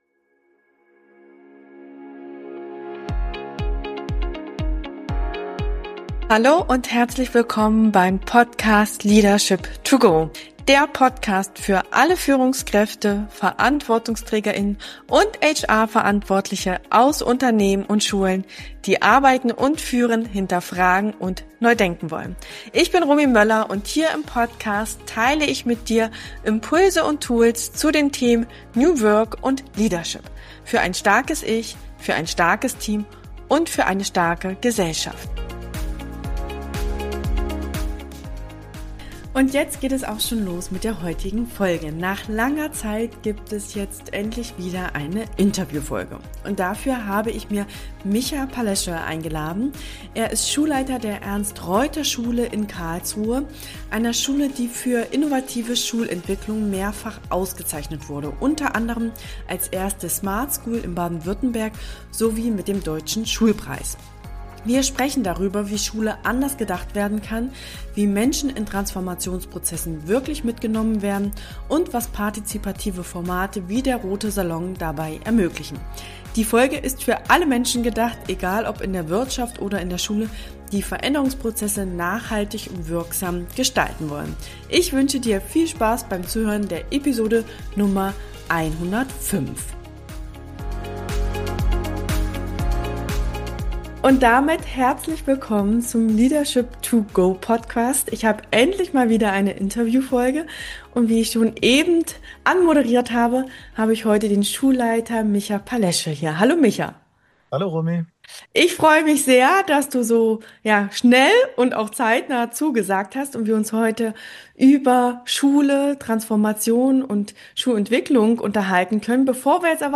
Warum Beteiligung kein „Nice-to-have“, sondern ein zentraler Hebel für Transformation ist, wird in diesem Gespräch sehr deutlich. Du erfährst, welche Haltung Führungskräfte brauchen, um Entwicklung zu ermöglichen, und was Organisationen aus dem System Schule lernen können.